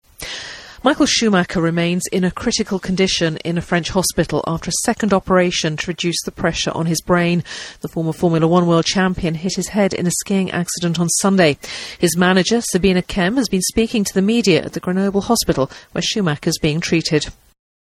【英音模仿秀】滑雪受伤送医 脑出血陷昏迷状态 听力文件下载—在线英语听力室